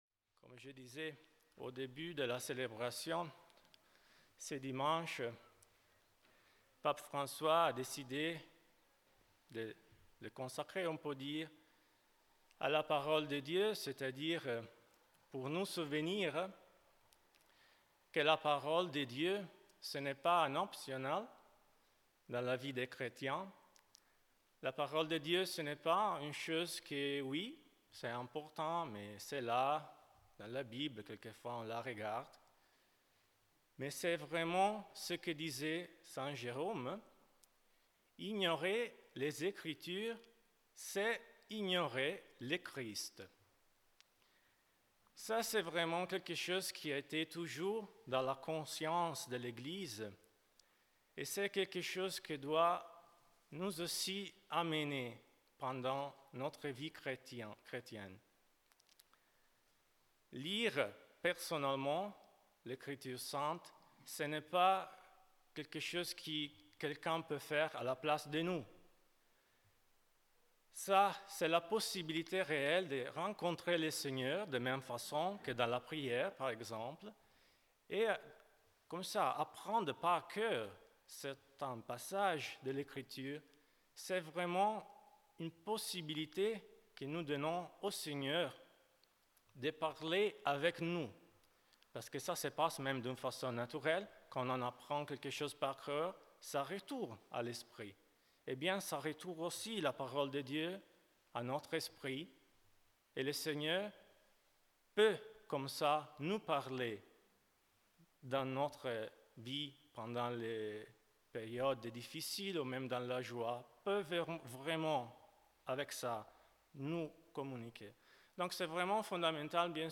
Enregistrement : l'homélie